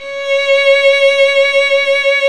Index of /90_sSampleCDs/Roland L-CD702/VOL-1/STR_Violin 2&3vb/STR_Vln2 % + dyn
STR  VL C#6.wav